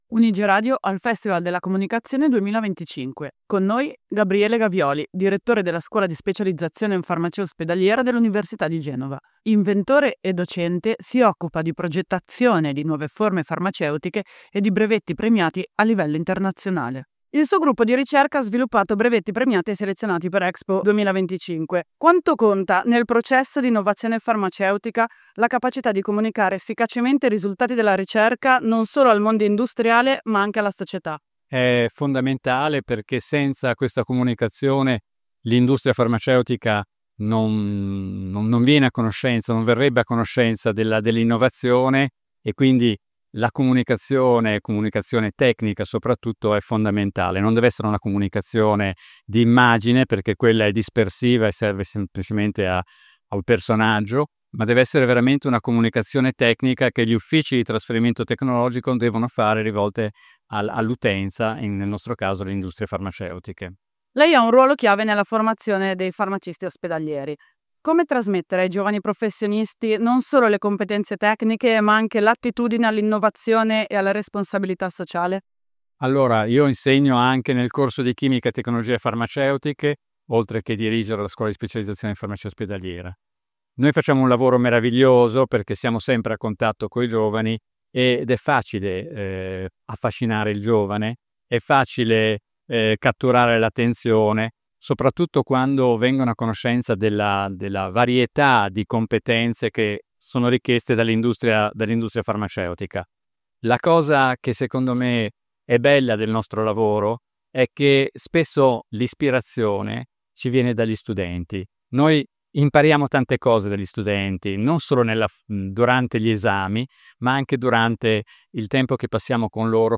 UniGE al Festival della Comunicazione 2025